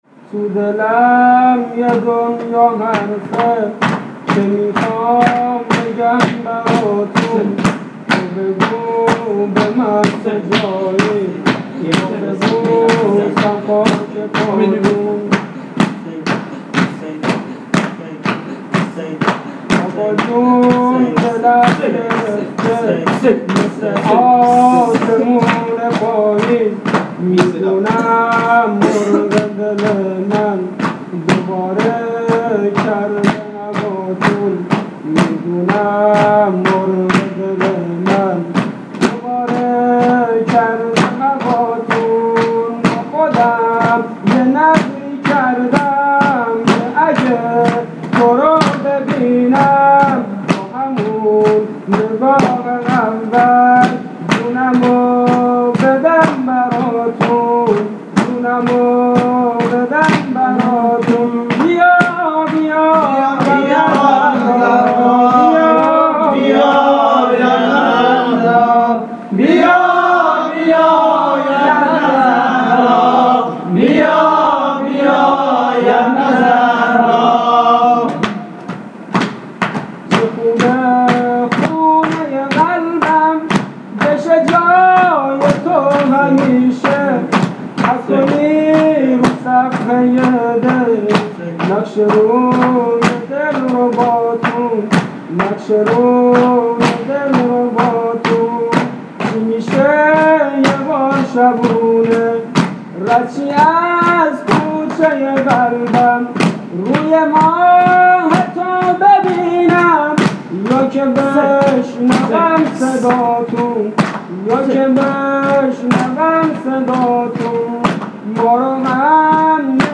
مداحی سه ضرب